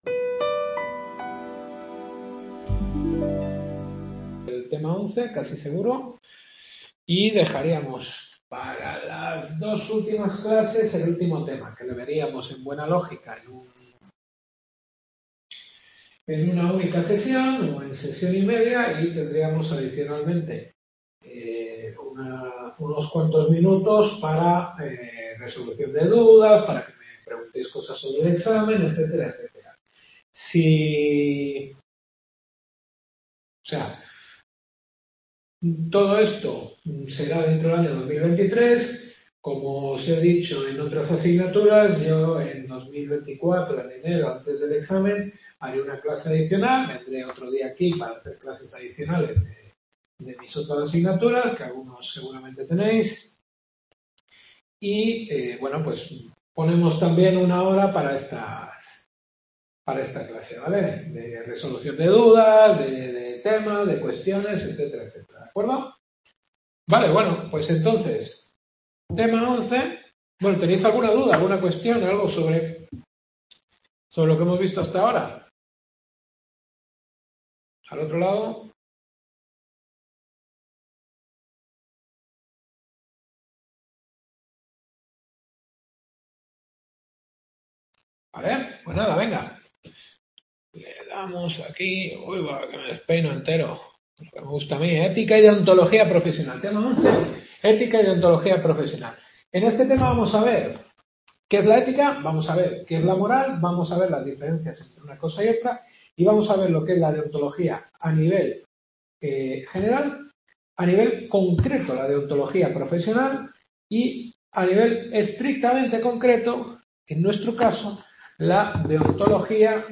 Teoría del Derecho-Introducción a la Teoría del Derecho. Décima clase.